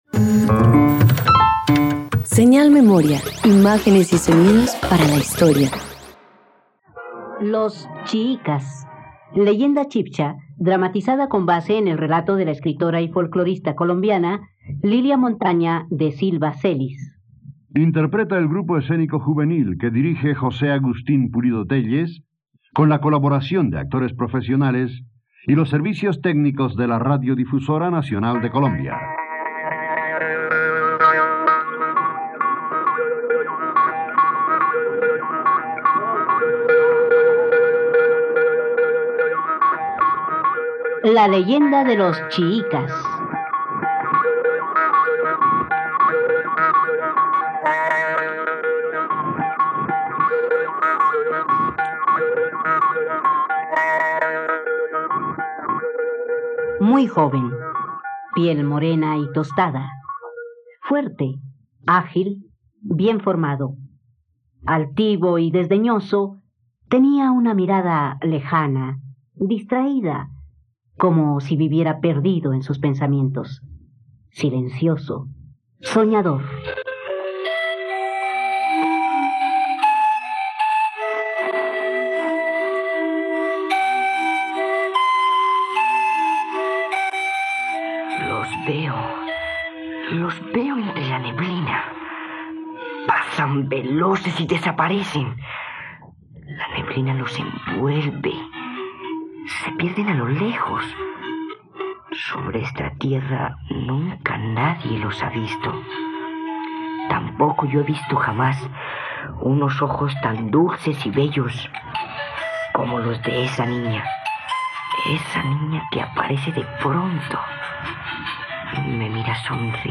..Radioteatro. Escucha la adaptación de "Los Chiicas" de la escritora y folclorista colombiana Lilia Montaña de Silva Celis.